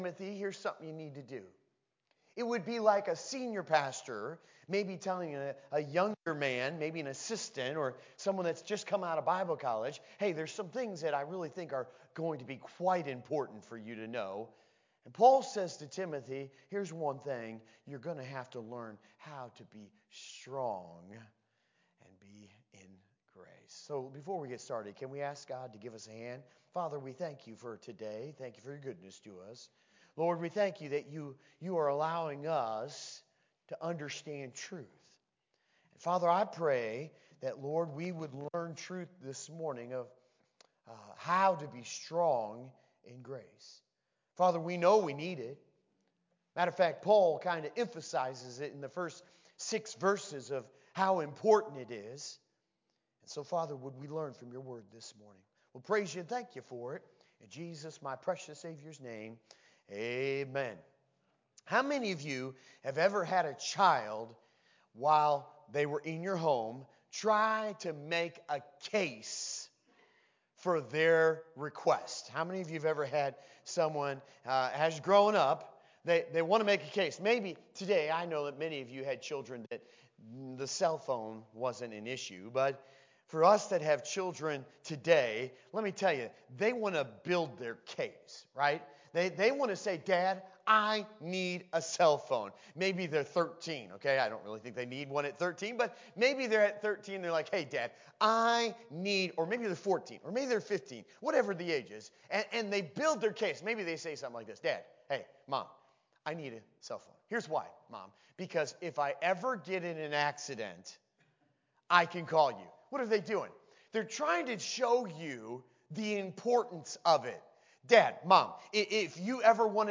June 30, 2019 – Sunday School